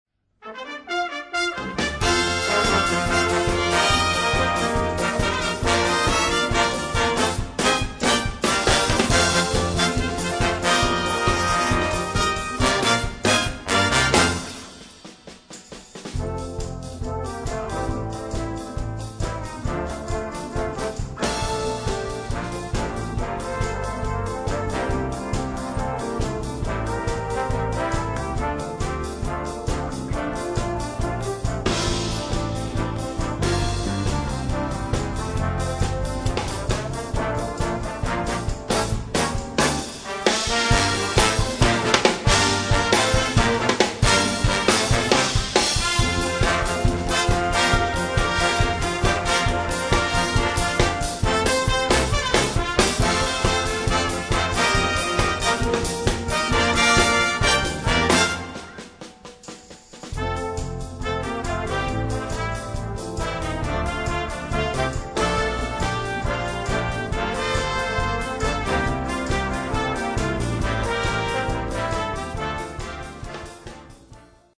Blaasorkest
Demo